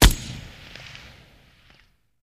M60 Machine Gun, Caliber 7.62 mm, Single Shot